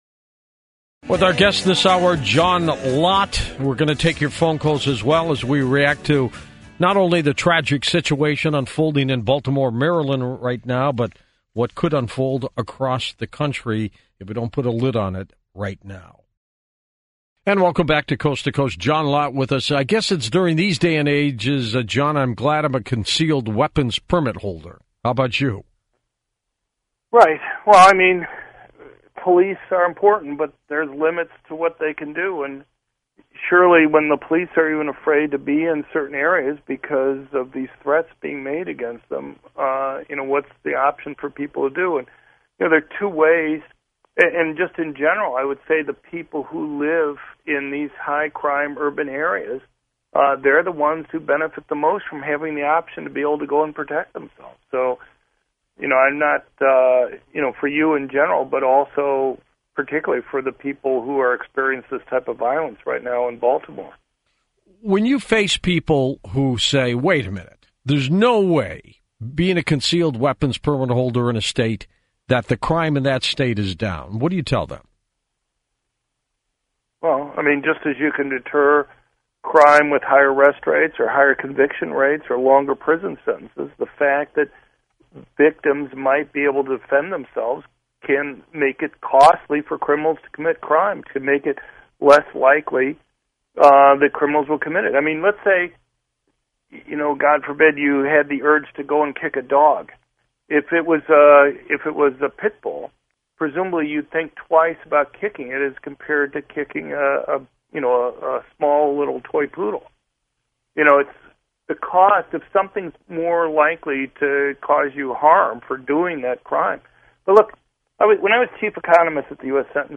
John Lott was on Coast-to-Coast AM with George Noory (weekly audience >2.75 million) for two hours on Tuesday morning to discuss the riots in Baltimore.